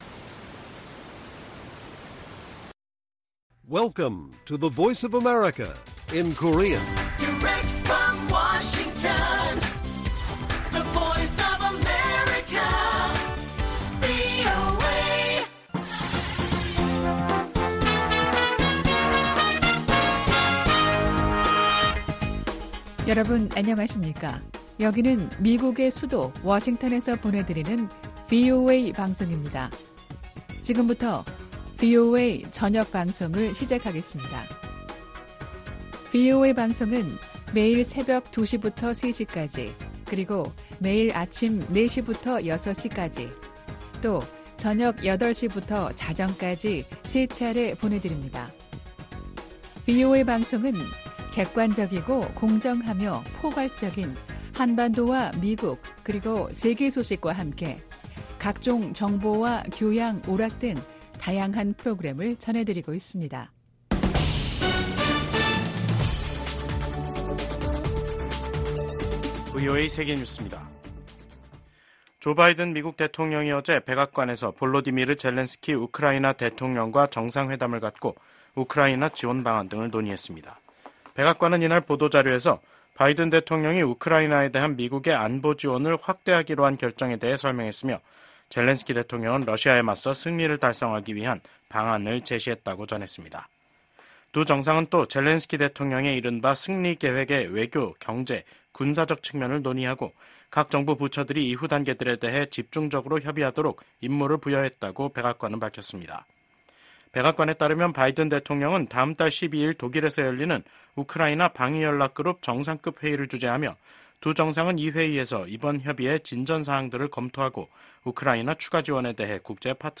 VOA 한국어 간판 뉴스 프로그램 '뉴스 투데이', 2024년 9월 27일 1부 방송입니다. 미국 대북 정책의 주요 요소는 종교와 신앙의 자유에 대한 정보를 포함한 북한 내 정보 접근을 확대하는 것이라고 미국 북한인권특사가 밝혔습니다. 미국과 영국, 호주의 안보협의체인 오커스가 첨단 군사기술 개발 협력 분야에서 한국 등의 참여 가능성을 논의 중이라고 확인했습니다.